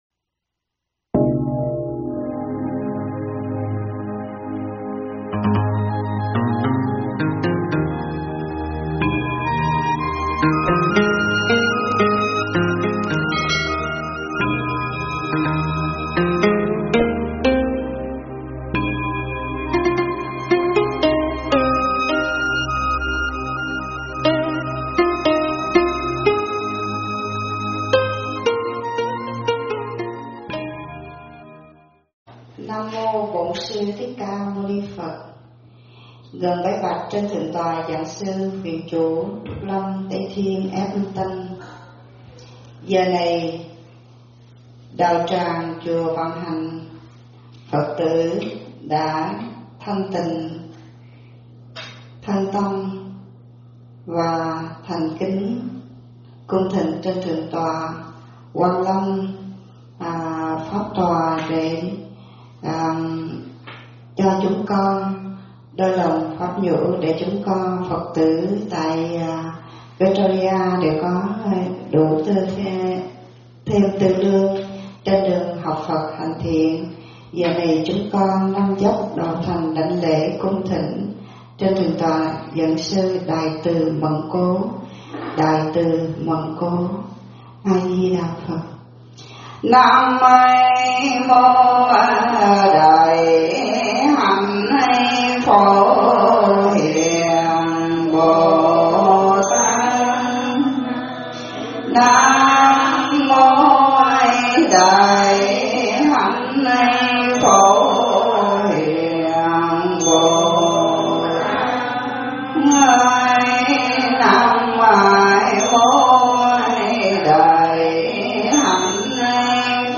thuyết pháp
giảng tại Chùa Vạn Hạnh, Victoria